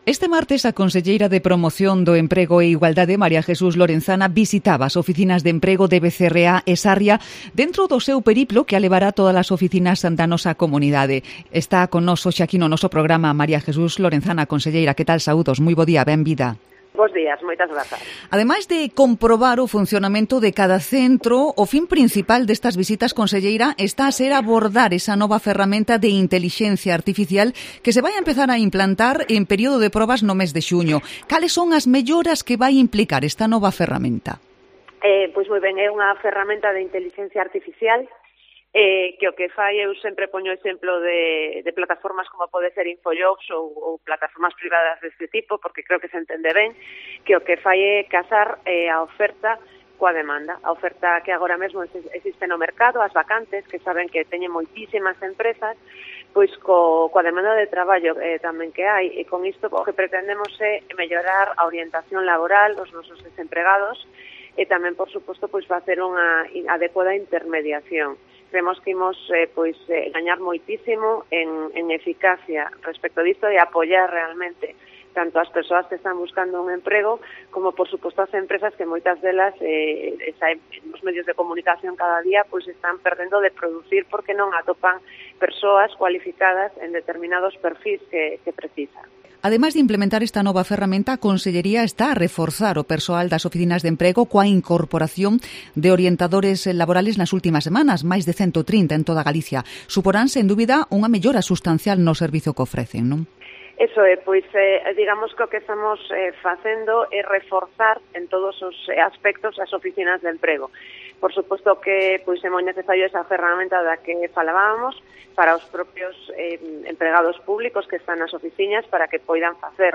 Entrevista a la conselleira de Promoción do Emprego e Igualdade